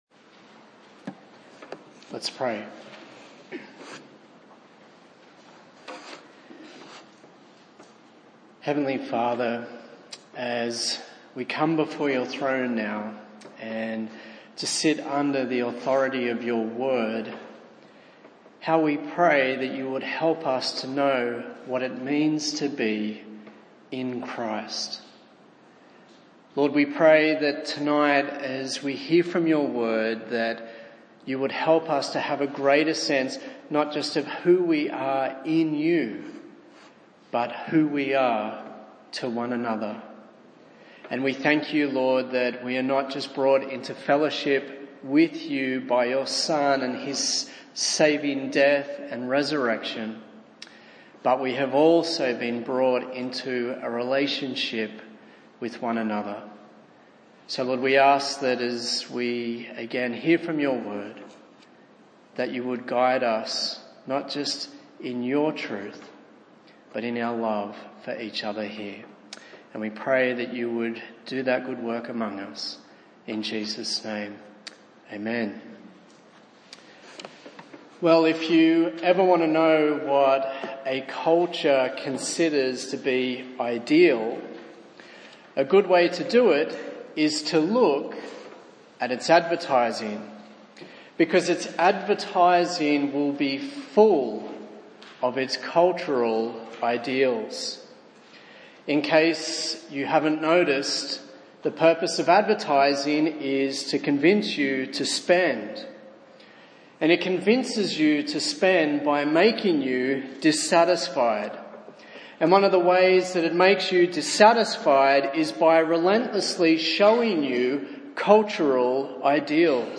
A sermon in the series on the book of 1 Thessalonians